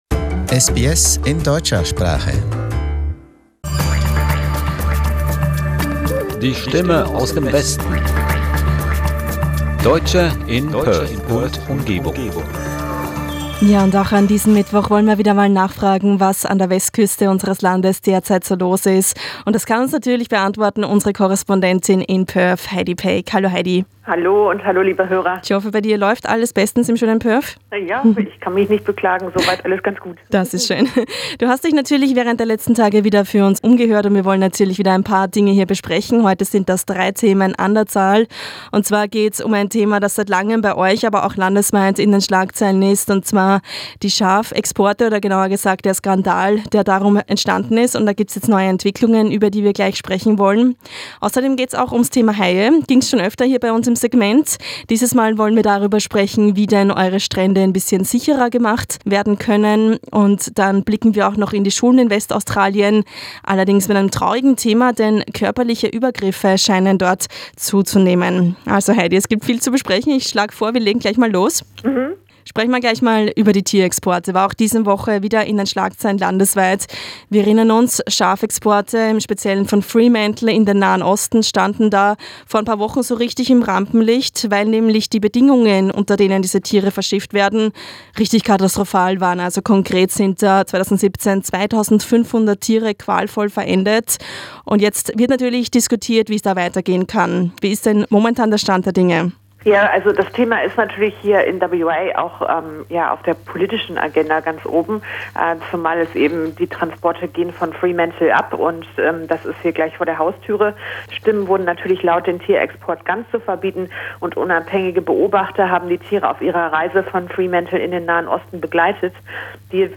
Korrespondentin